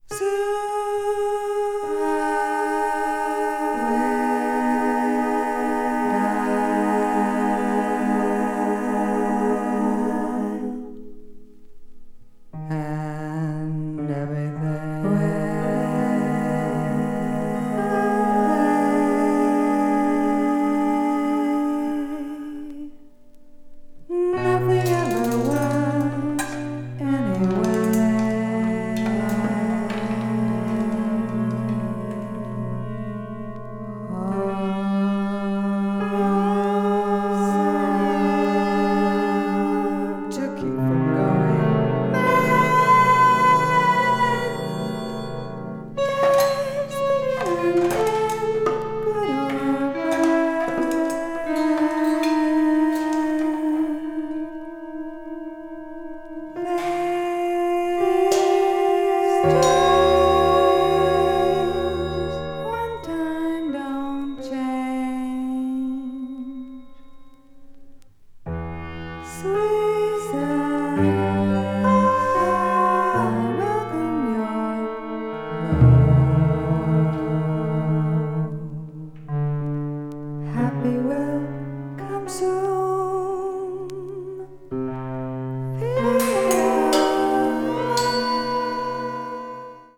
avant-garde   avant-jazz   contemporary jazz   jazz vocal